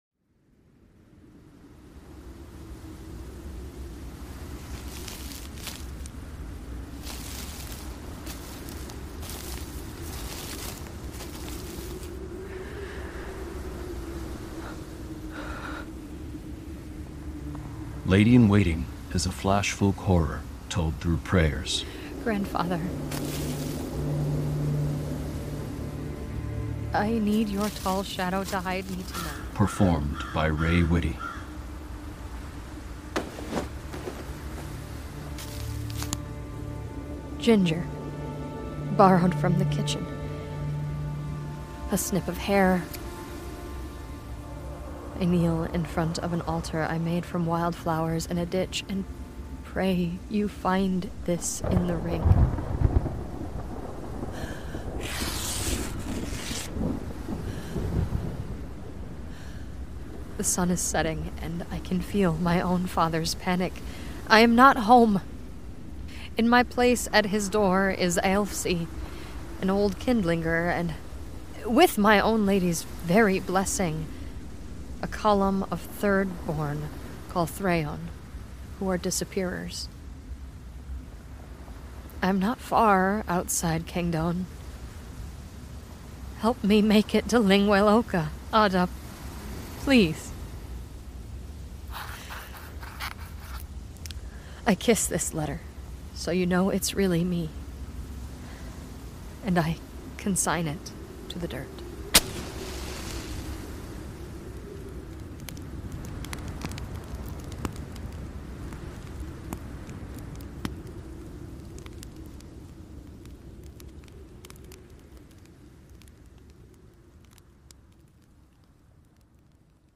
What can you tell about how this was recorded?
This is an immersive audio drama.